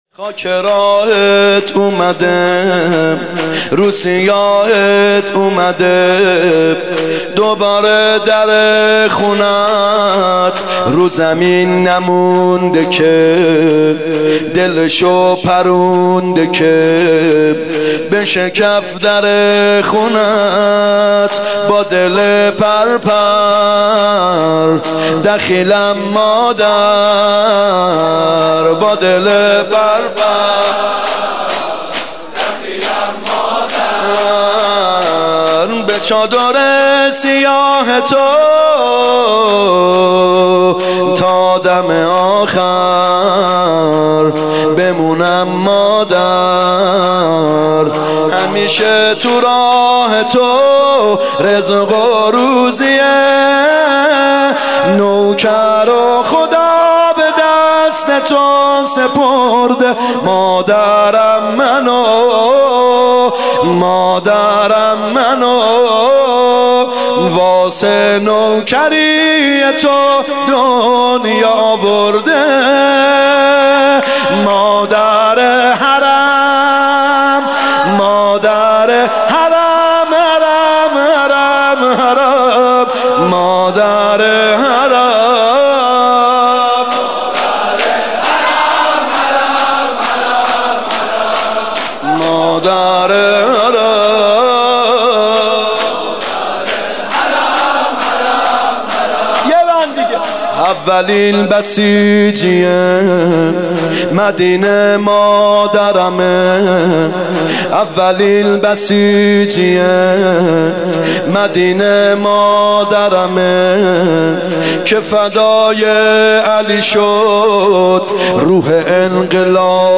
فاطمیه اول 95 شور ( خاک راهت اومده روسیاهت اومده
فاطمیه هیات یامهدی عج